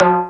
DX Timbale 01.wav